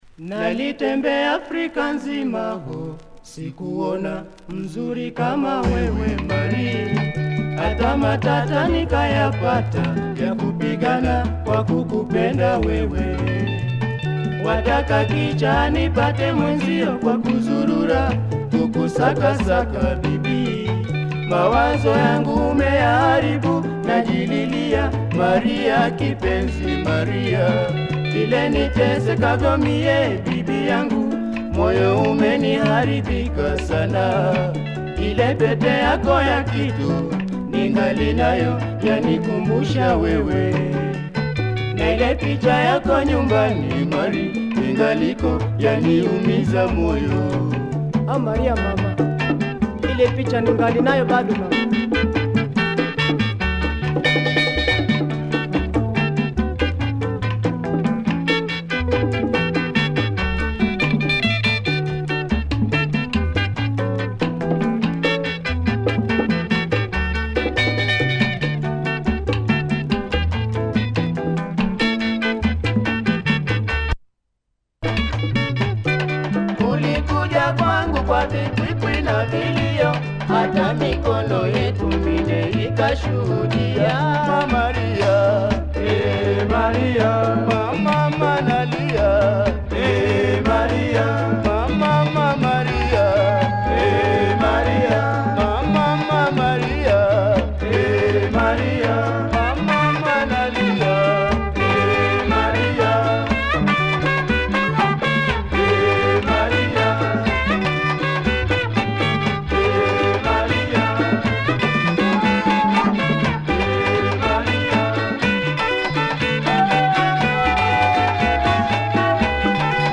but this Zambia duo who recorded and lived in Kenya